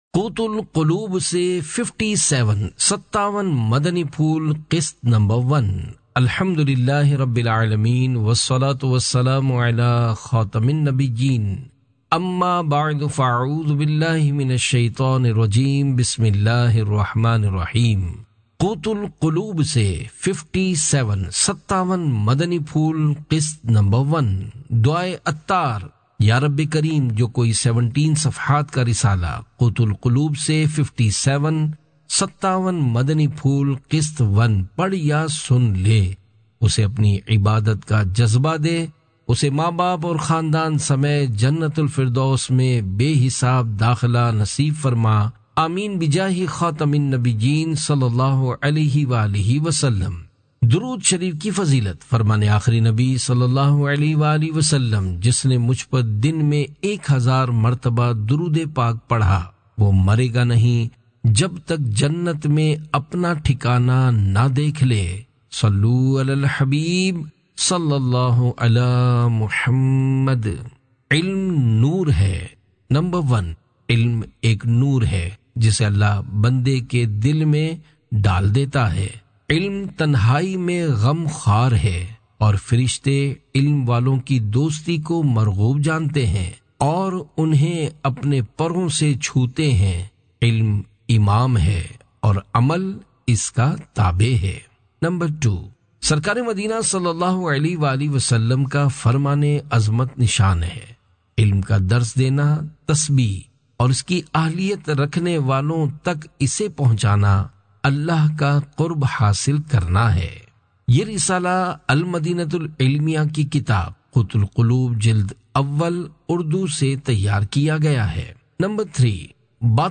آڈیو بک – قوت القلوب سے 57 مدنی پھول (قسط 01)